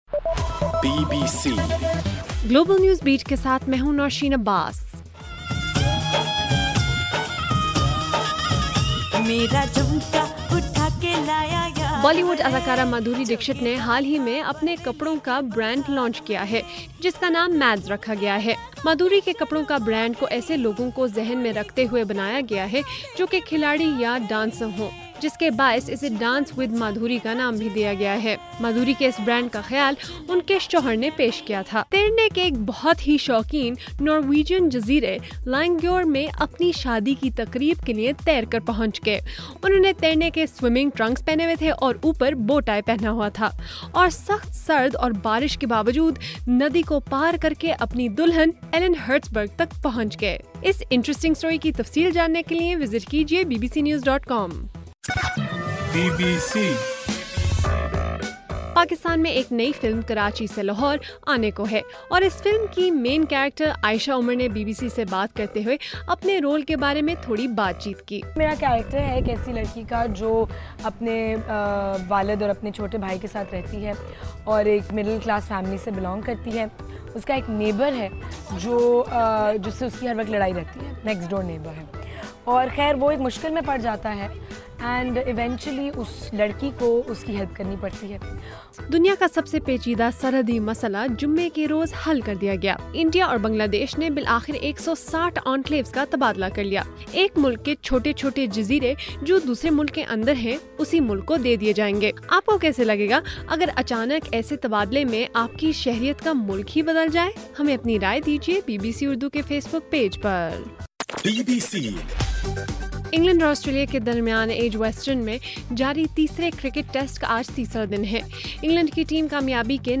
جولائی 31: رات 9 بجے کا گلوبل نیوز بیٹ بُلیٹن